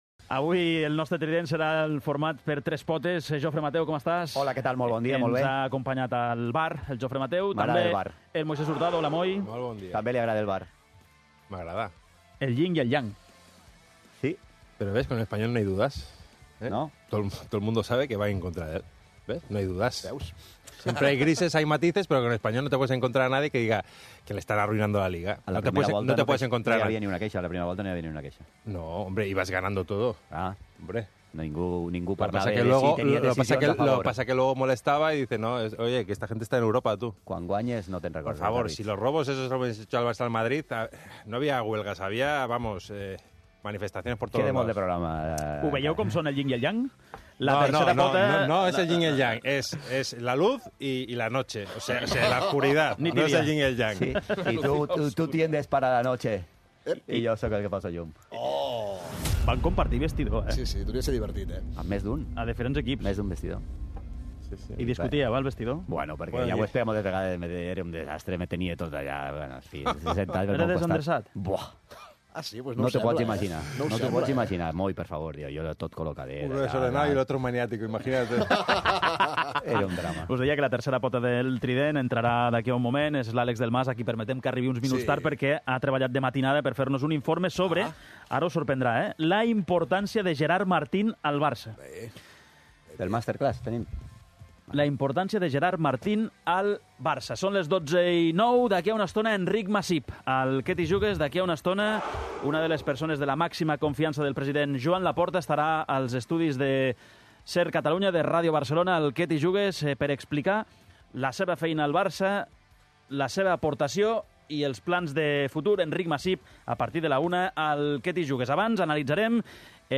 Al 'Què t'hi Jugues!' fem tertúlia